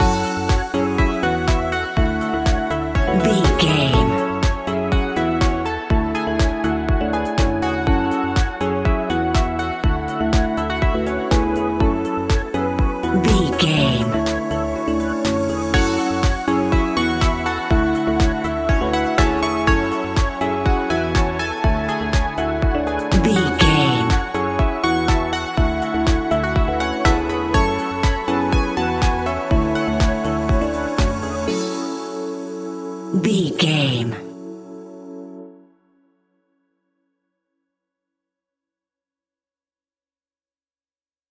Aeolian/Minor
F#
groovy
happy
piano
drum machine
synthesiser
house
electro house
funky house
synth leads
synth bass